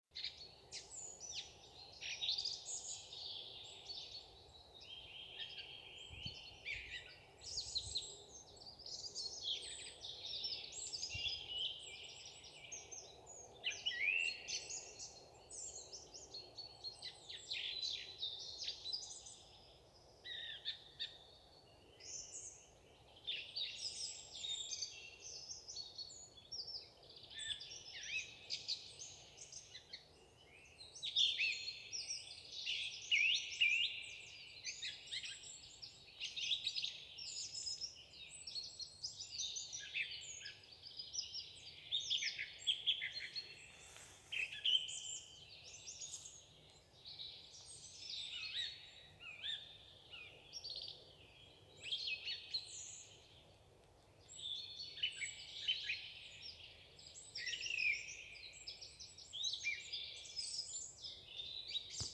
Birds -> Thrushes ->
Song Trush, Turdus philomelos
StatusSinging male in breeding season